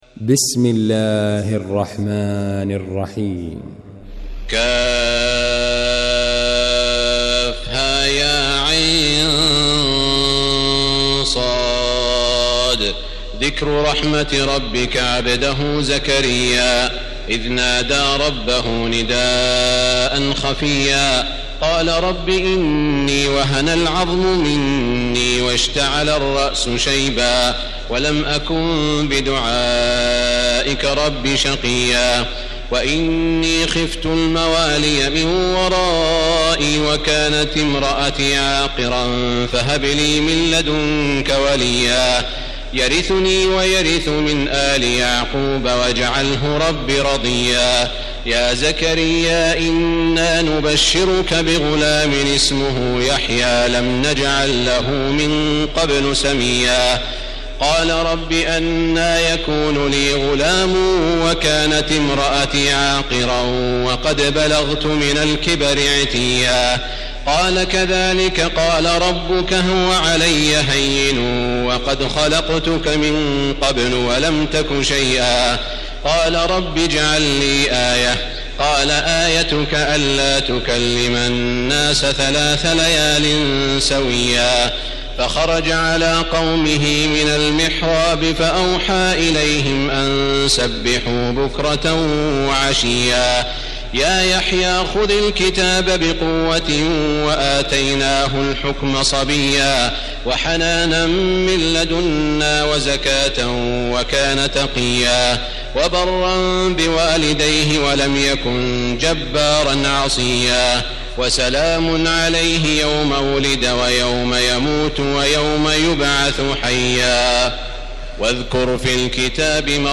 المكان: المسجد الحرام الشيخ: سعود الشريم سعود الشريم مريم